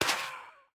Minecraft Version Minecraft Version latest Latest Release | Latest Snapshot latest / assets / minecraft / sounds / block / soul_sand / break2.ogg Compare With Compare With Latest Release | Latest Snapshot
break2.ogg